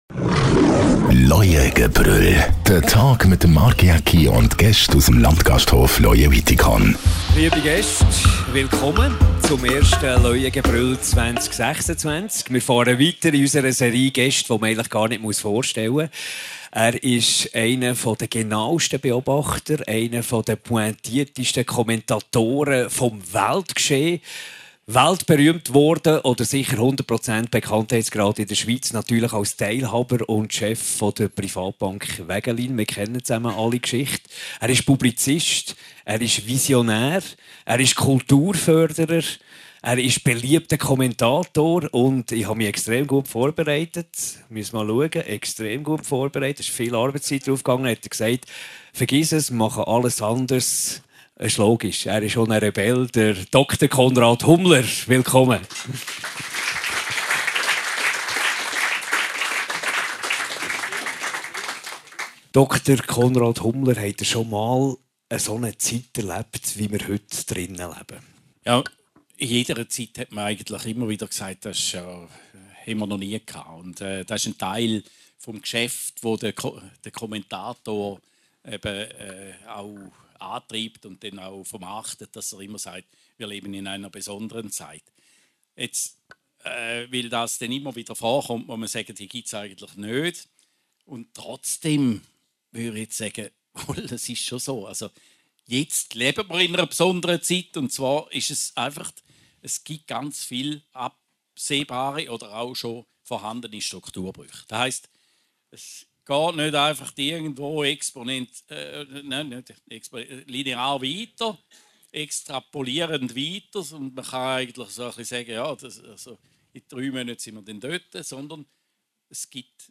Talksendung mit verschieden Persönlichkeiten aus Wirtschaft, Sport und Entertainment